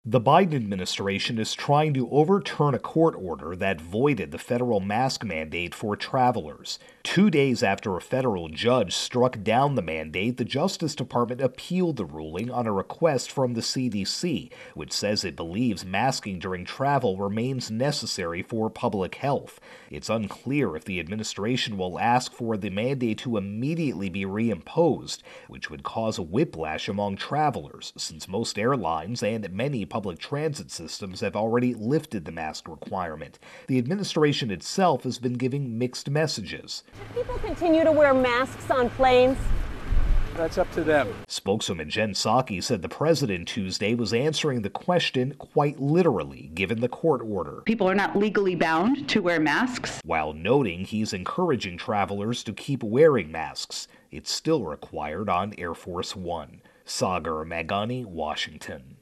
Travel-Mask Mandate intro and wrap.